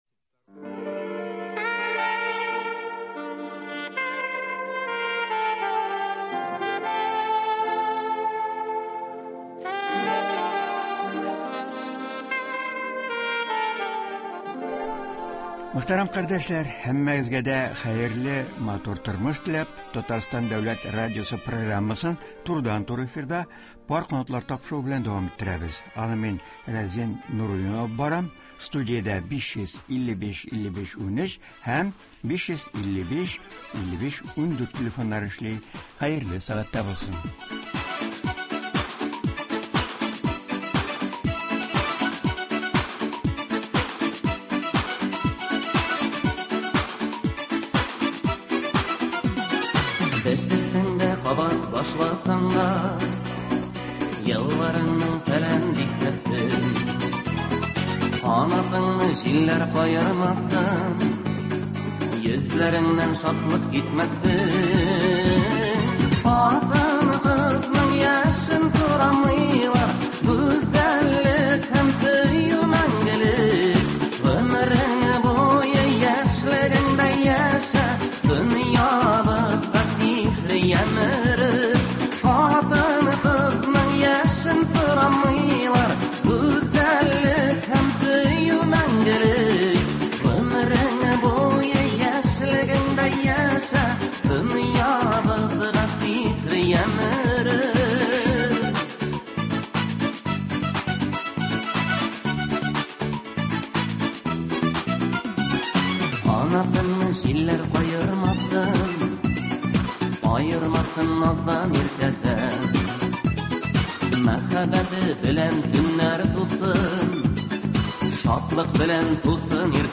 Турыдан-туры элемтә тапшыруында